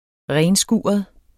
renskuret adjektiv Bøjning -, renskurede Udtale [ -ˌsguːʌð ] Betydninger 1. grundigt rengjort, især med børste eller svamp Behandlingen begynder med en varm overskylning og indsæbning af kyndige hænder fra top til tå.